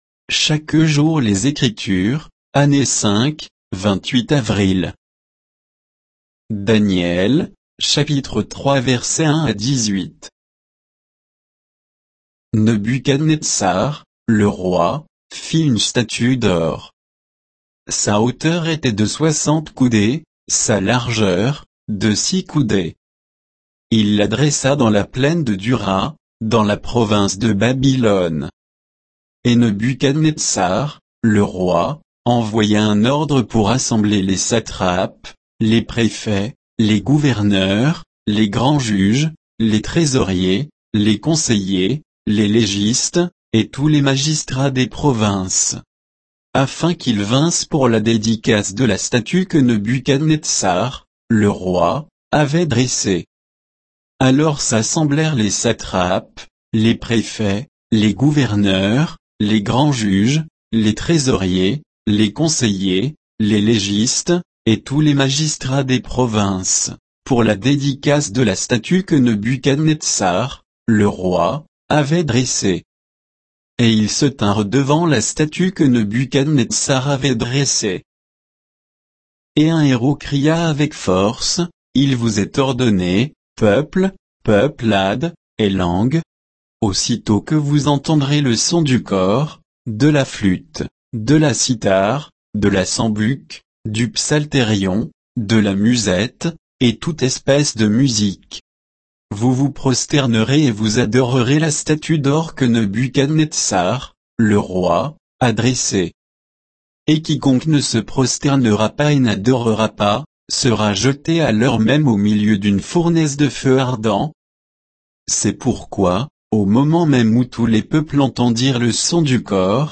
Méditation quoditienne de Chaque jour les Écritures sur Daniel 3